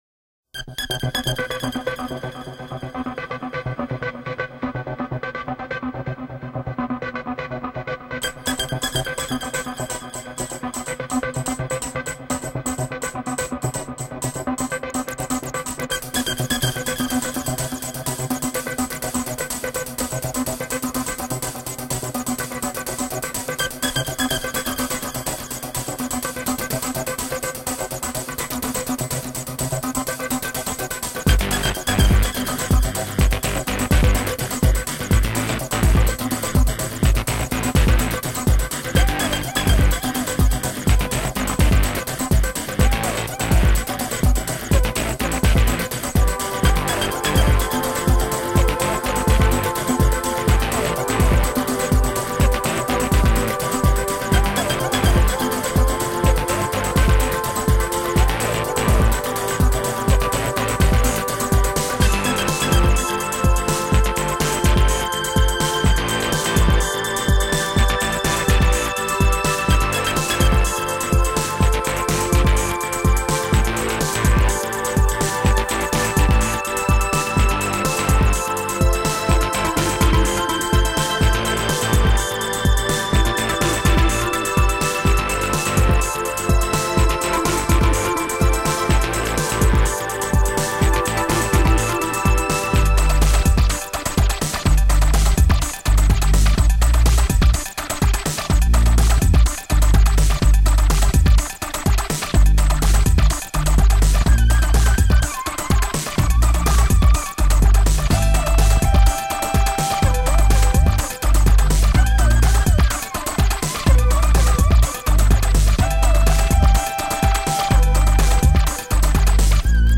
Musique d'ambiance du niveau 7... loop ... bass ... zen